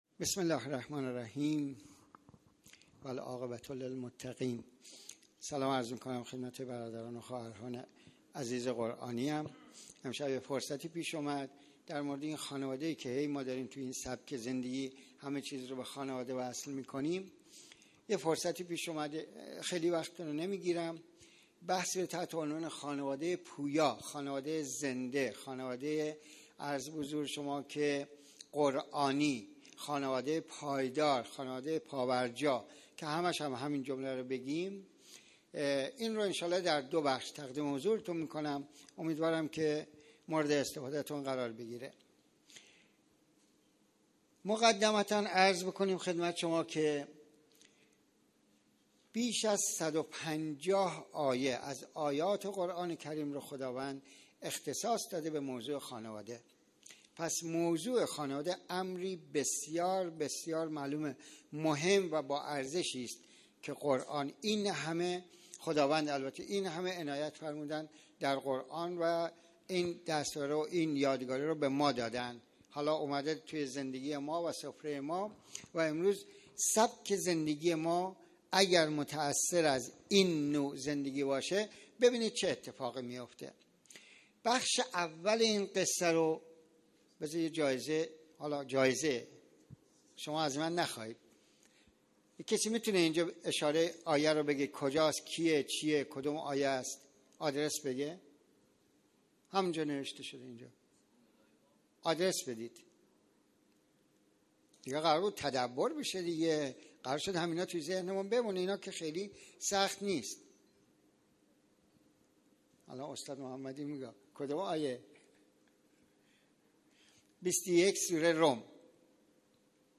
گزارش صوتی دویست و نود و پنجمین کرسی تلاوت و تفسیر قرآن کریم - پایگاه اطلاع رسانی ضیافت نور